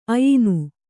♪ ayinu